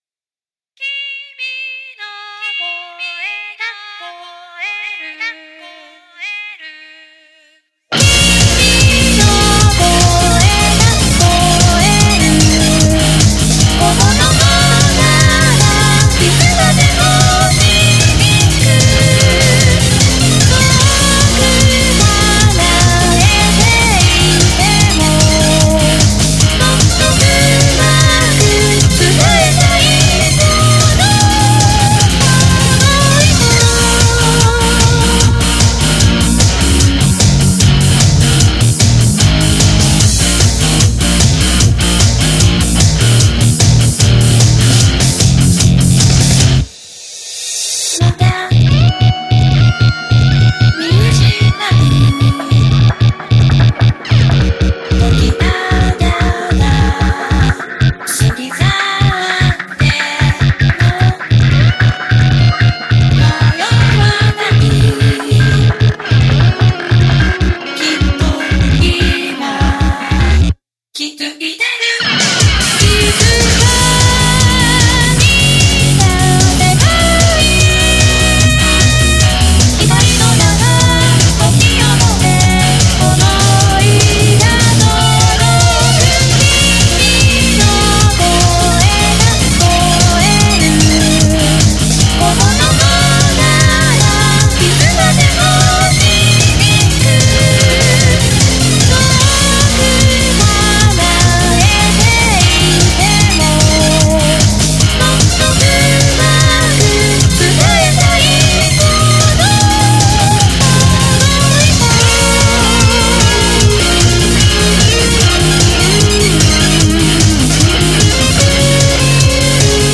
ボーカロイド（VOCALOID）等を使用した無料楽曲です。
デジタリックなハードロック曲です。